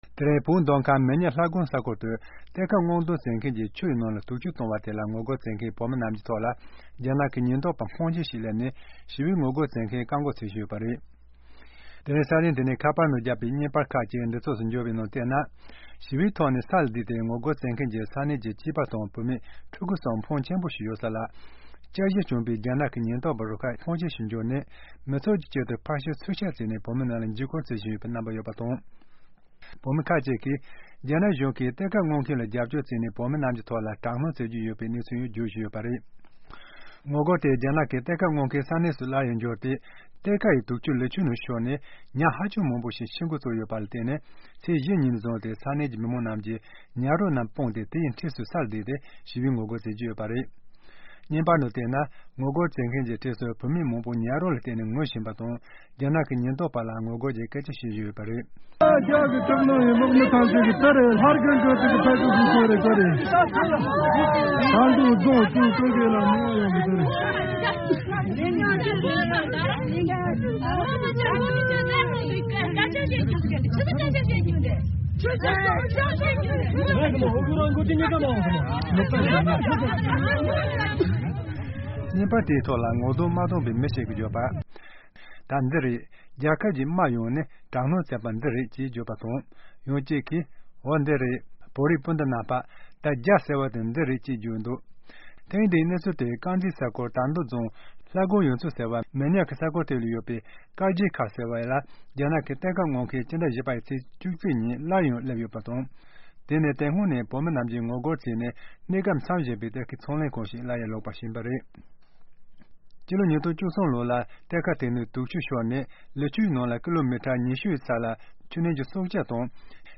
Cell phone video circulated online captures the sound of people wailing at the site of a mass fish kill, purportedly caused the mining operation that Chinese police swooped in to guard.